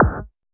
HARDQ VRB.wav